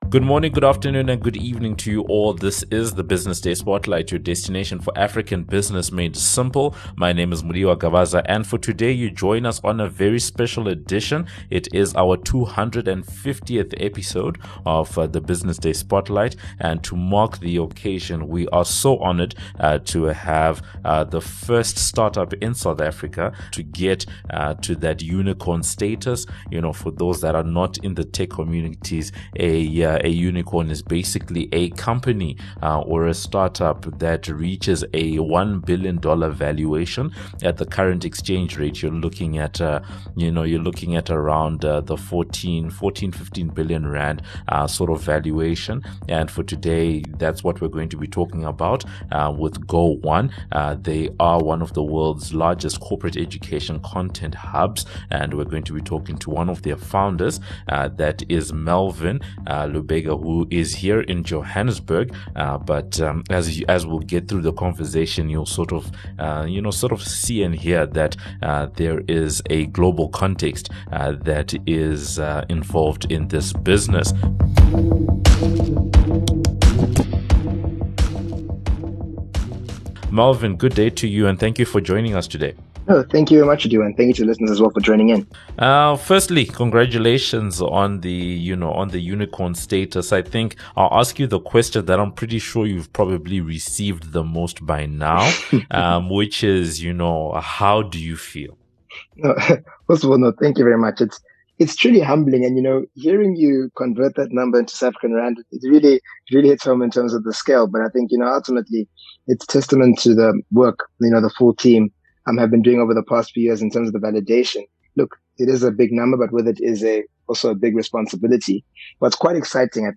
18 Aug In conversation with SA's first unicorn